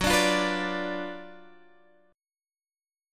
GbM7sus4#5 chord